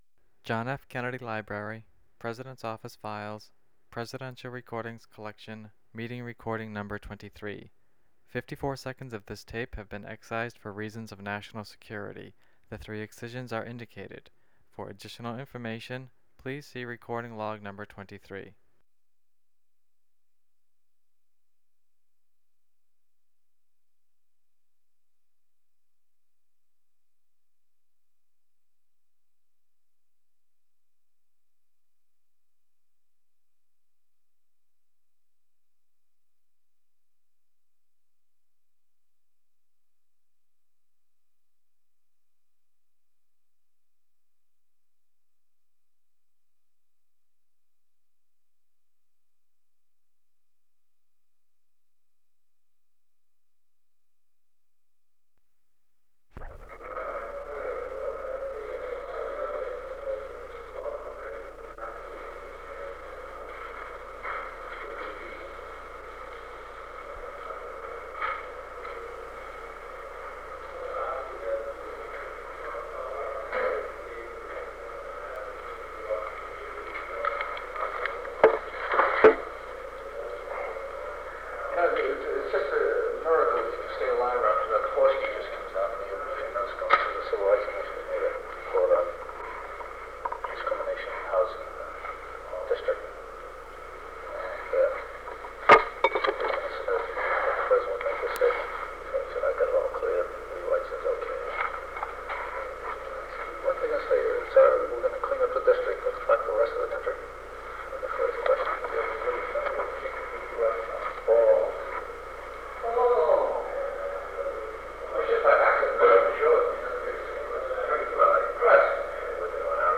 Secret White House Tapes | John F. Kennedy Presidency Meeting with Maxwell Taylor on His Far Eastern Trip Rewind 10 seconds Play/Pause Fast-forward 10 seconds 0:00 Download audio Previous Meetings: Tape 121/A57.